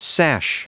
Prononciation du mot sash en anglais (fichier audio)
Prononciation du mot : sash